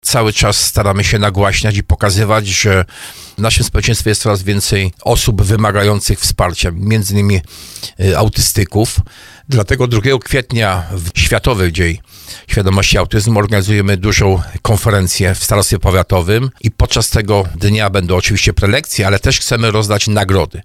Starosta tarnowski Jacek Hudyma tłumaczy, że chodzi o tolerancję.